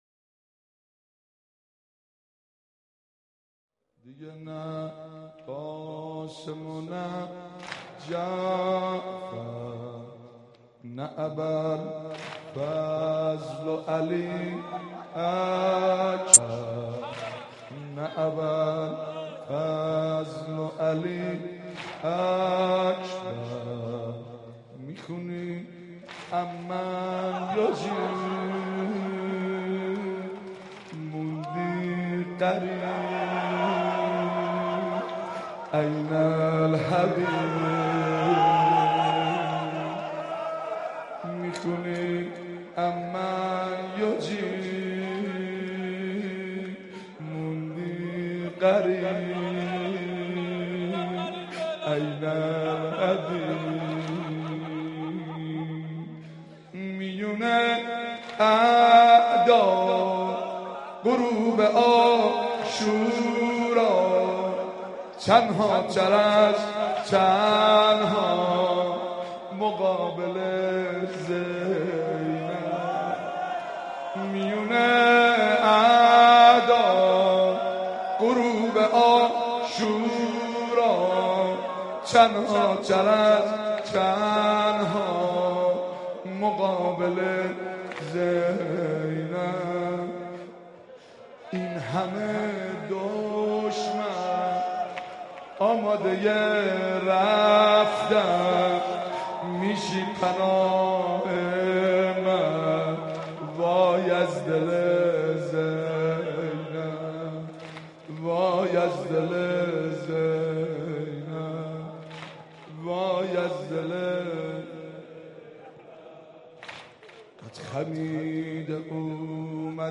محرم 88 - سینه زنی 7
محرم-88---سینه-زنی-7